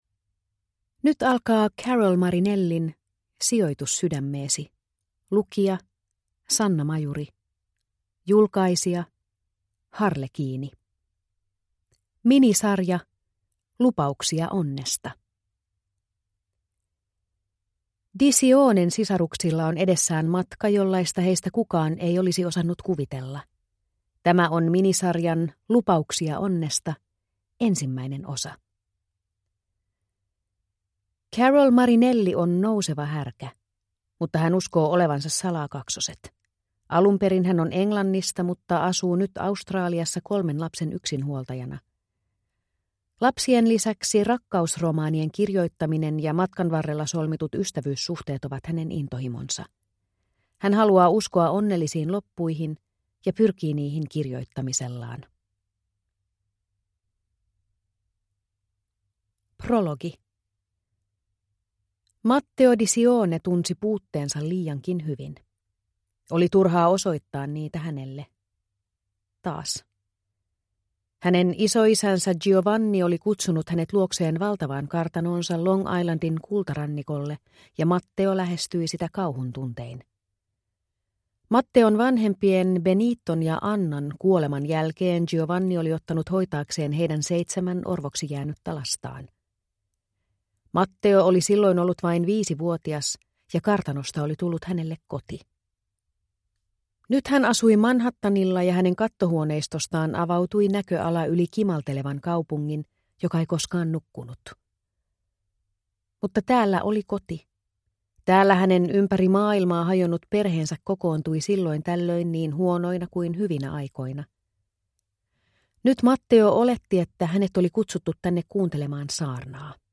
Sijoitus sydämeesi (ljudbok) av Carol Marinelli